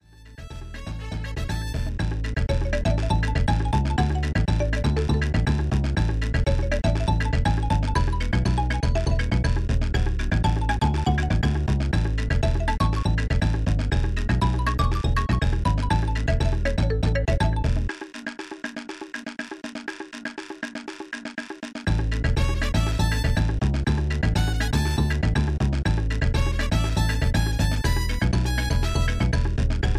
File selection music